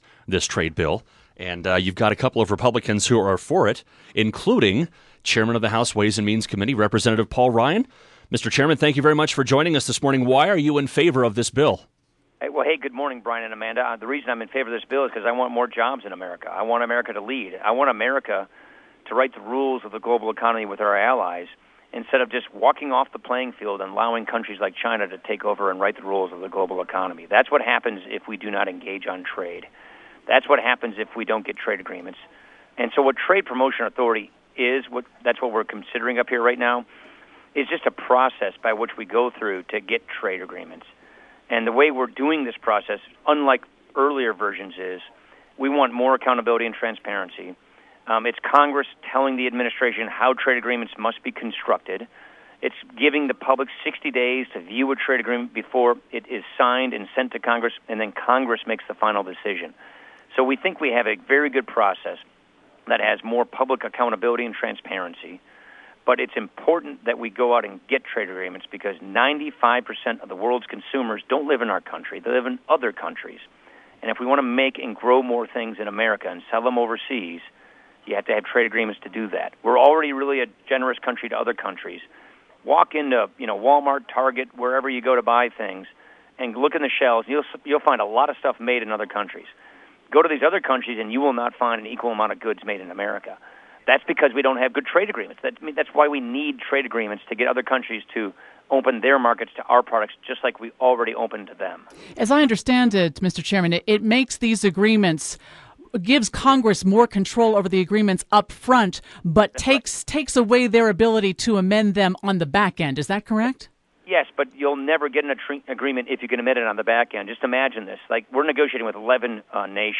Interview
President Obama is pushing for a trade deal that he says will open up markets to US businesses overseas, but he's facing opposition in his own party. A number of republicans support it, including House Ways and Means Committee Chairman Paul Ryan (R-WI), who joined Utah's Morning News live Wednesday morning.